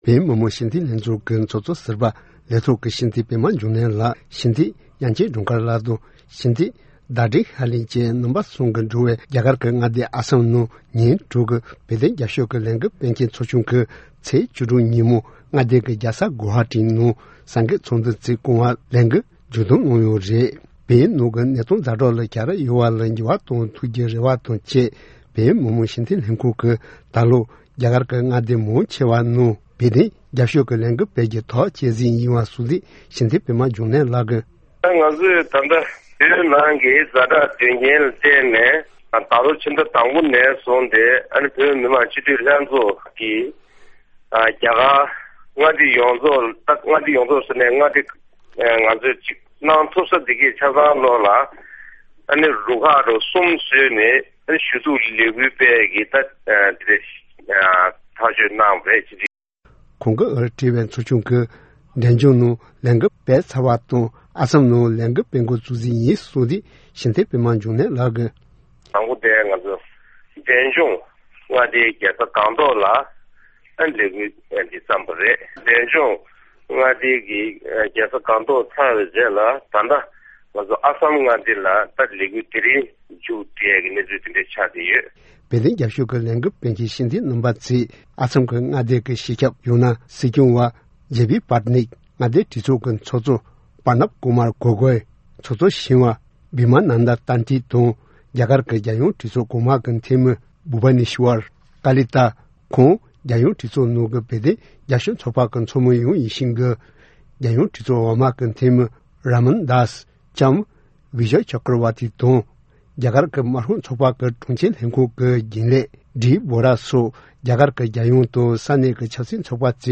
བཀའ་འདྲི་ཞུ་ནས་གནས་ཚུལ་ཕྱོགས་བསྒྲིགས་བྱས་པ་ཞིག་སྙན་སྒྲོན་ཞུ་ཡི་རེད།།